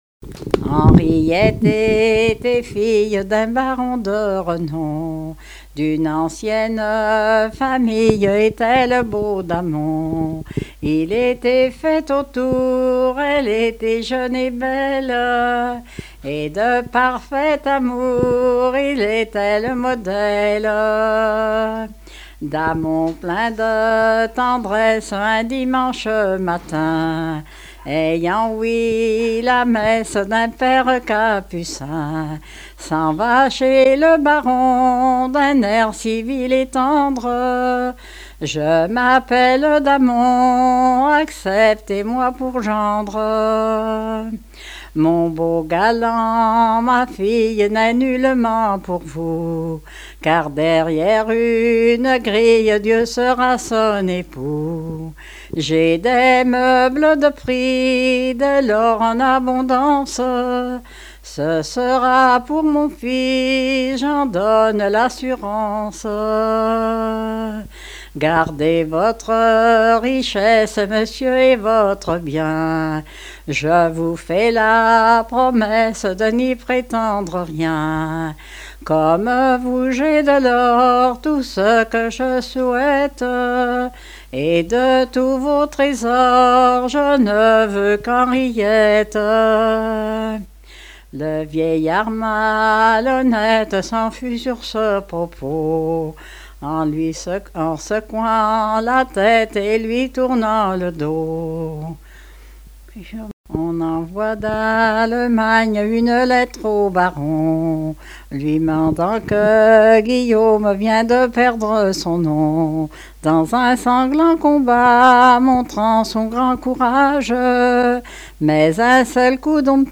Genre strophique
Enquête Arexcpo en Vendée
Pièce musicale inédite